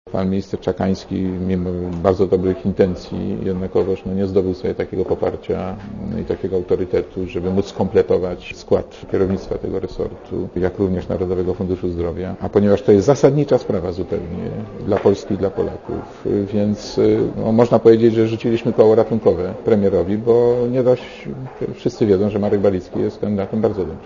Mówi Marek Borowski